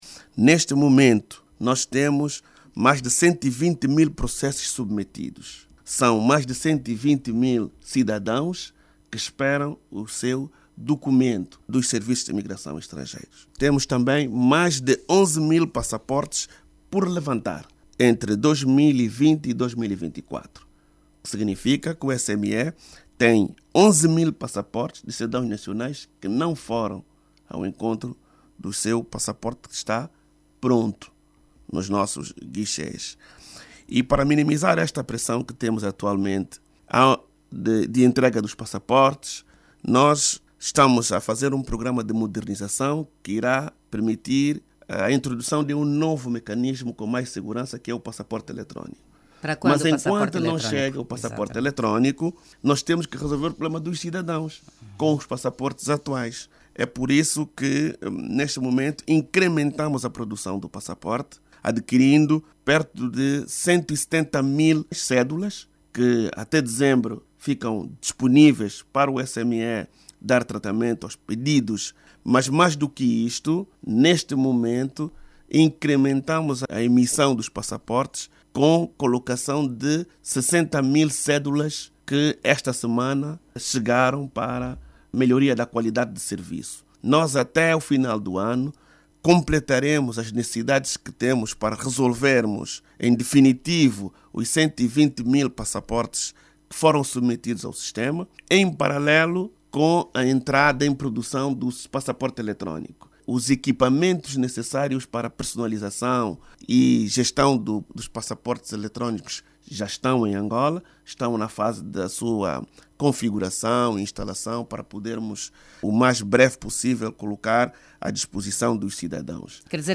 Em entrevista exclusiva à RNA, Manuel Homem anunciou que, para fazer face à pressão no SME, está em curso um programa de modernização que vai também introduzir o passaporte electrónico.